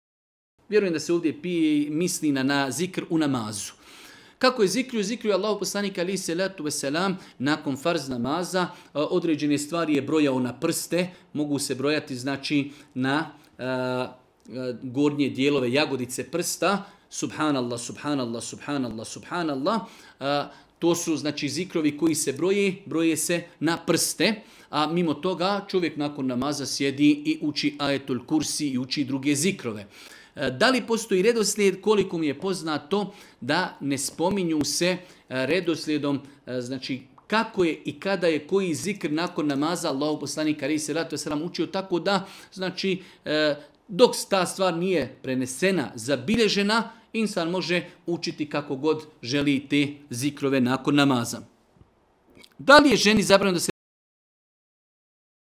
u video predavanju ispod.